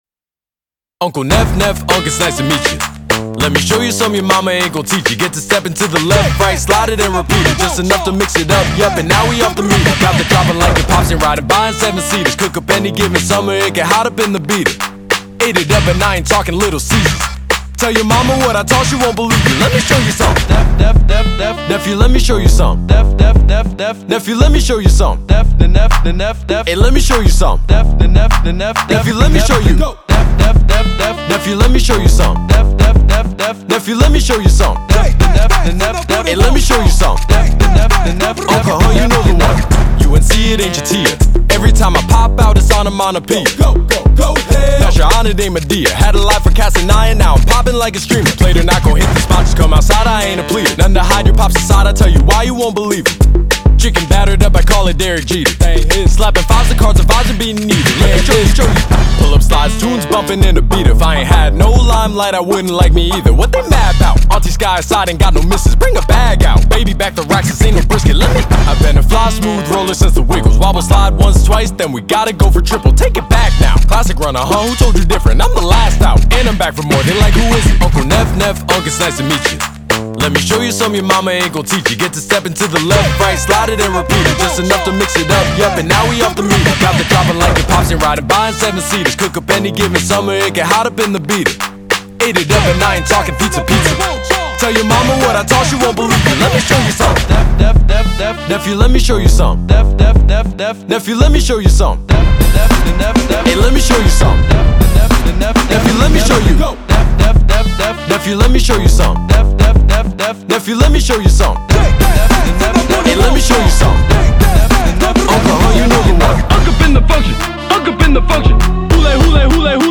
BPM100-100
Audio QualityPerfect (High Quality)
Rap song for StepMania, ITGmania, Project Outfox
Full Length Song (not arcade length cut)